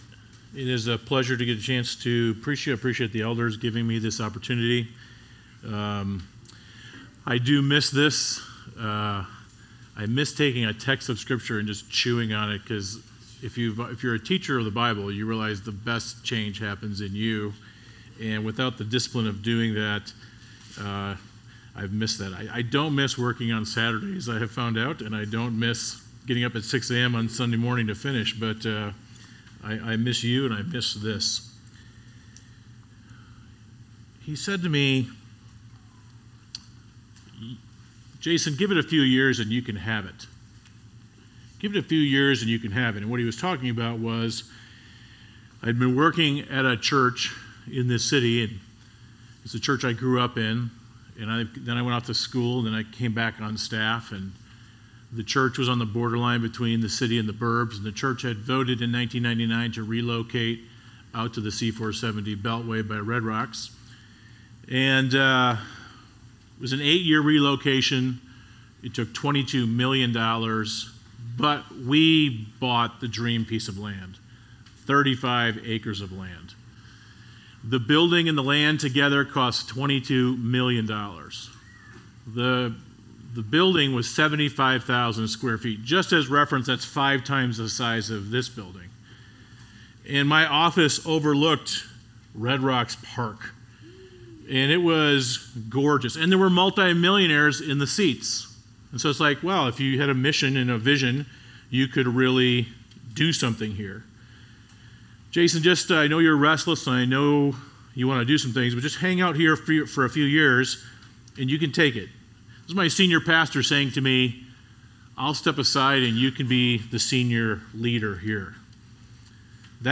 Sermons – Providence Bible Church